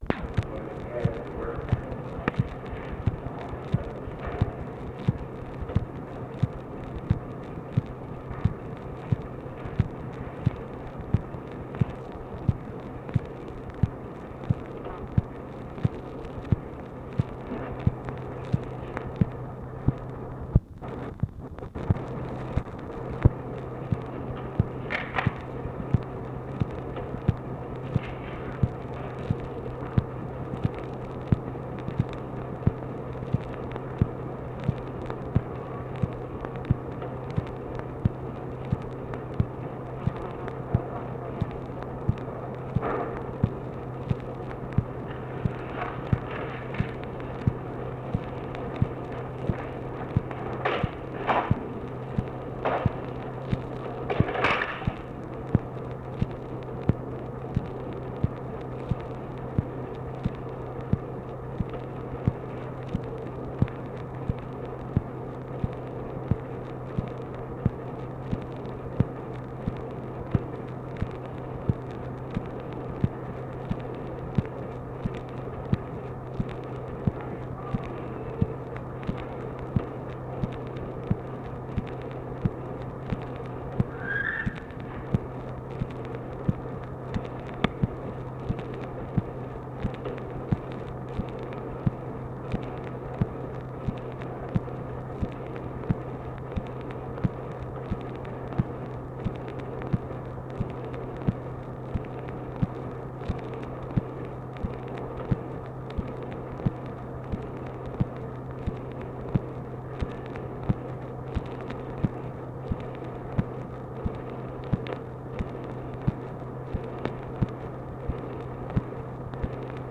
OFFICE NOISE, June 12, 1964